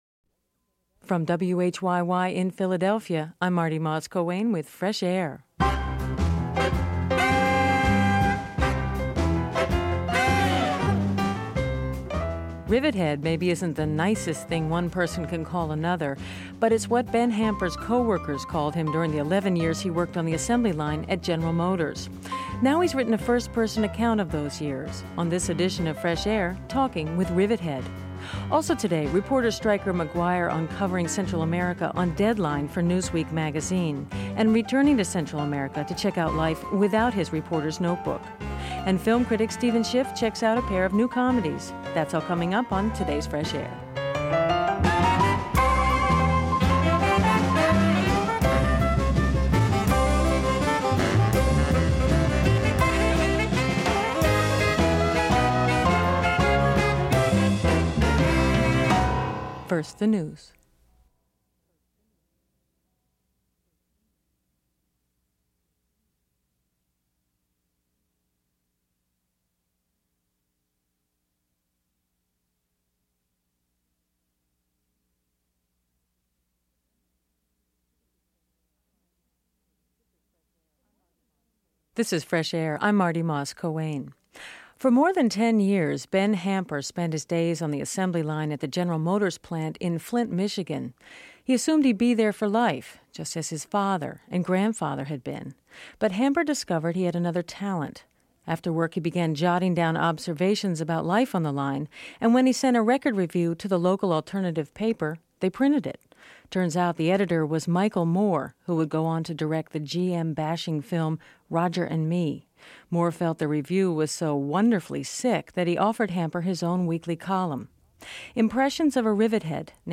Actor and comedian Martin Short